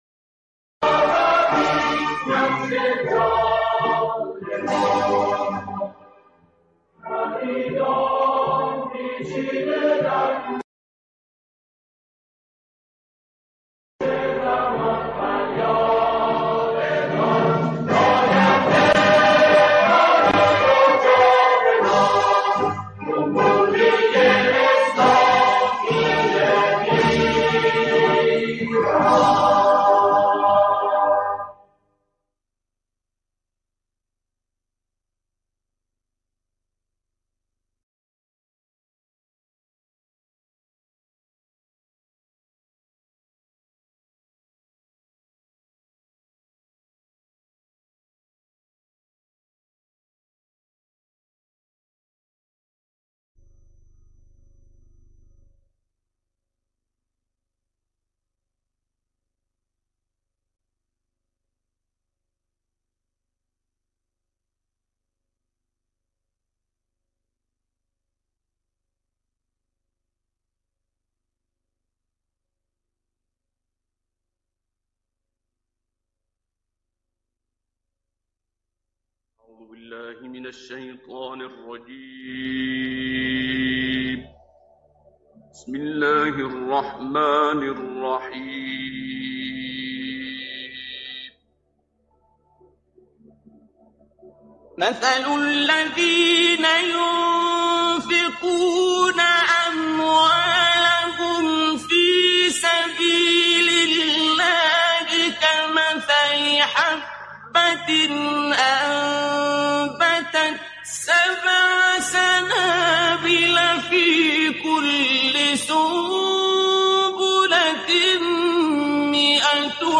مجمع عمومی عادی بطور فوق العاده شرکت مبین وان کیش - نماد: اوان